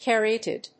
音節car・y・at・id 発音記号・読み方
/k`æriˈæṭɪd(米国英語)/